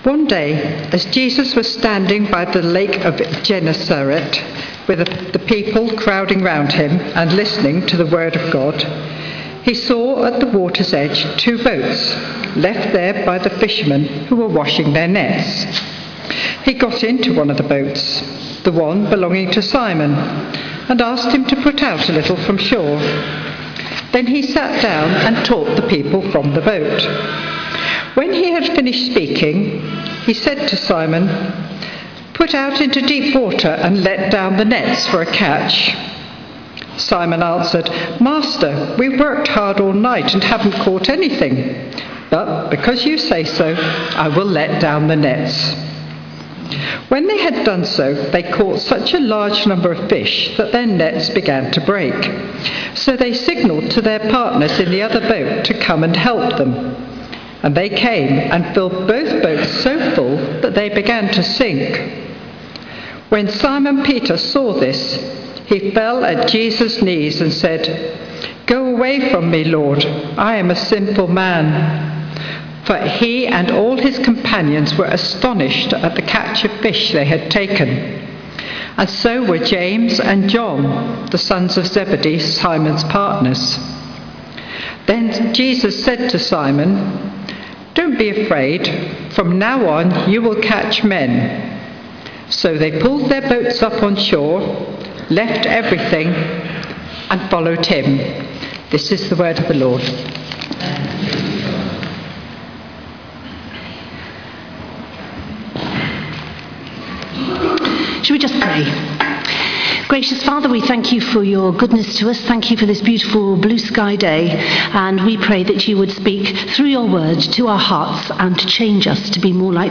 Service Type: Sunday 11:00am